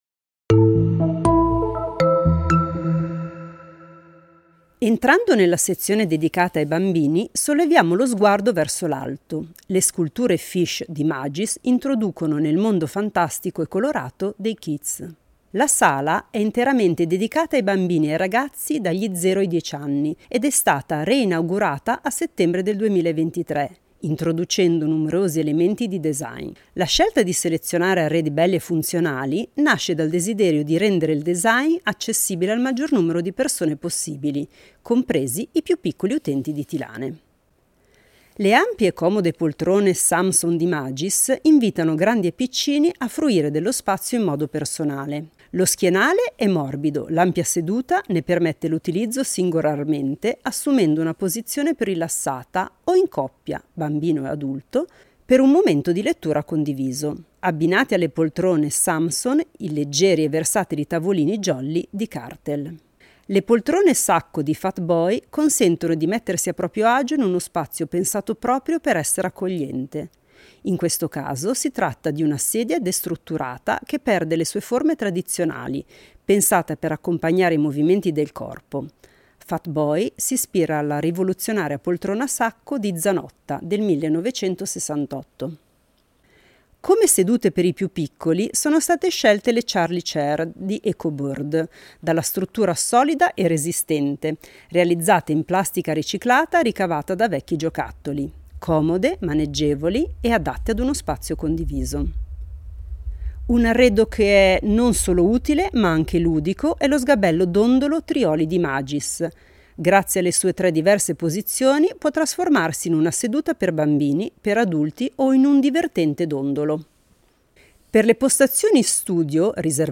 Visita guidata ad alta voce